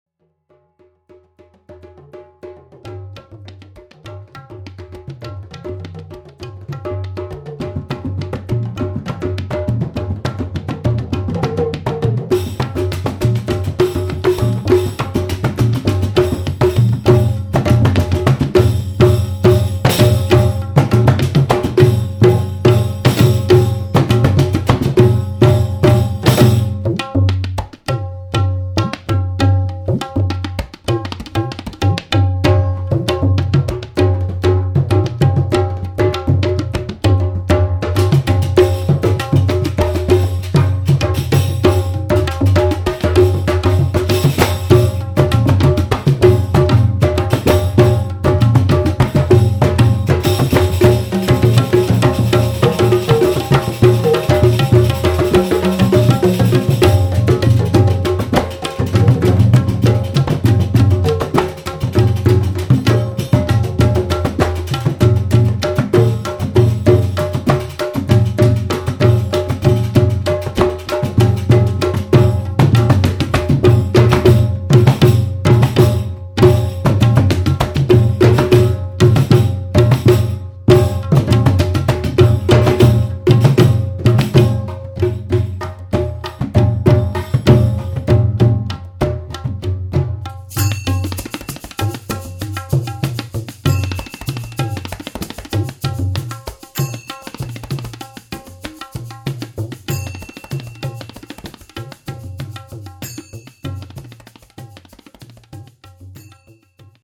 cymbals, sogo, djembe, talking drum, shaker, goob-goobi
tabla, doumbek, rebolo, bongos